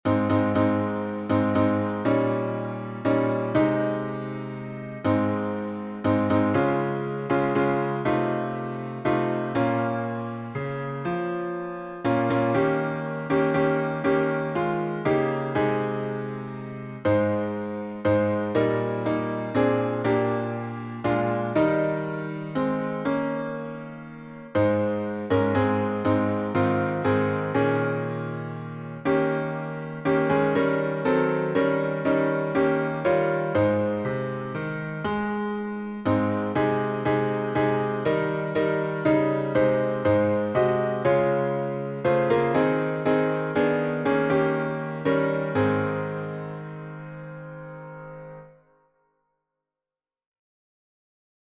Key signature: A flat major (4 flats) Time signature: 6/8